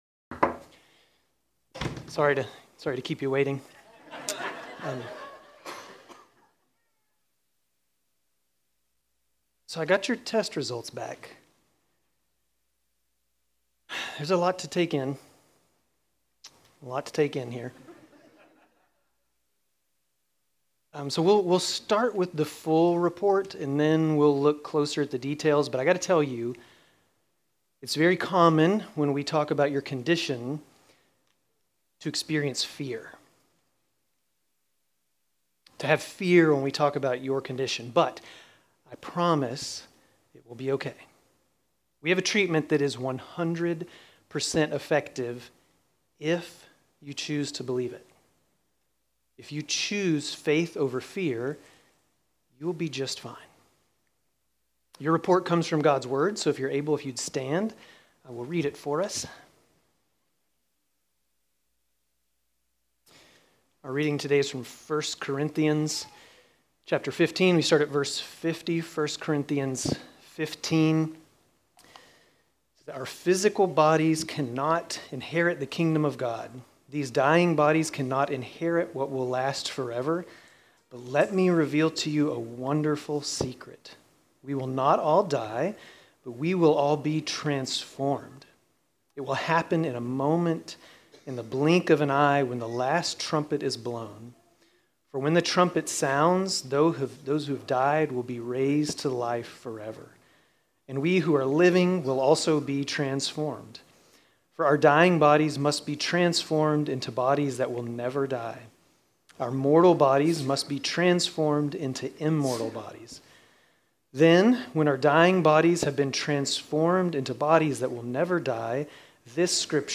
Grace Community Church Dover Campus Sermons 5_25 Dover Campus May 26 2025 | 00:28:51 1x 00:00 / 00:28:51 Subscribe Share RSS Feed Share Link Embed